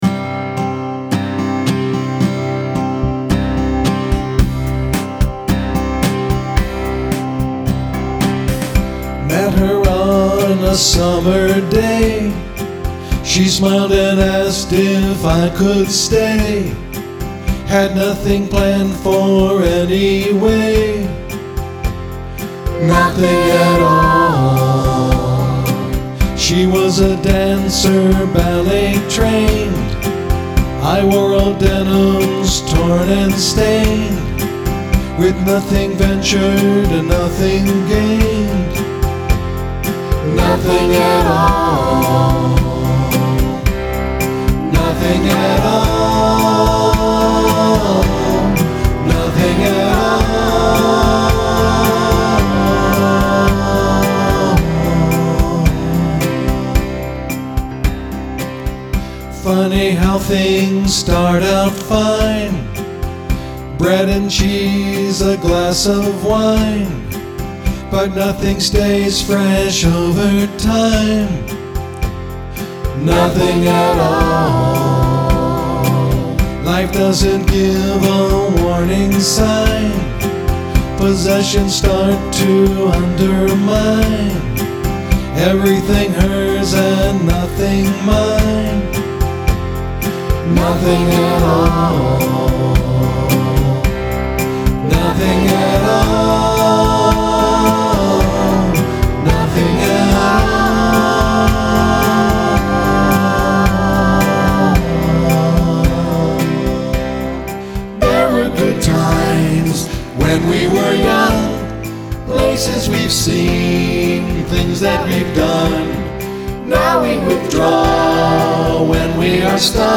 catchy melodies with inspiring lyrics